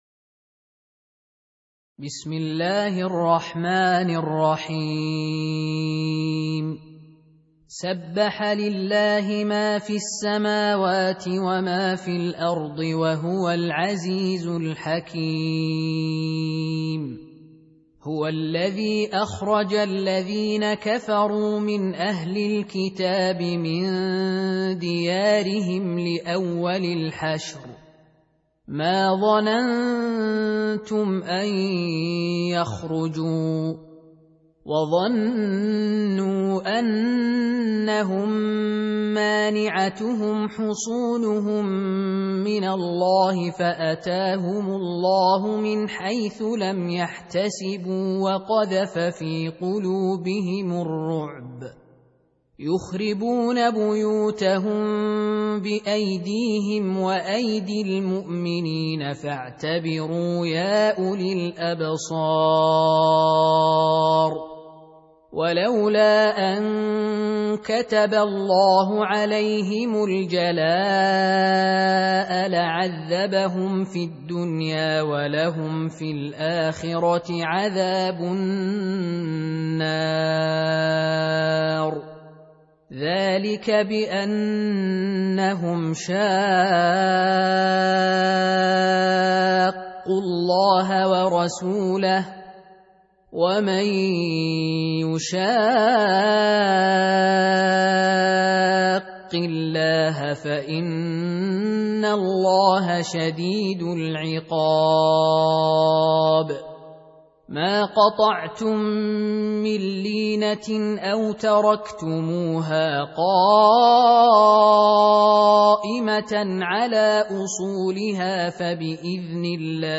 59. Surah Al-Hashr سورة الحشر Audio Quran Tarteel Recitation
Surah Repeating تكرار السورة Download Surah حمّل السورة Reciting Murattalah Audio for 59.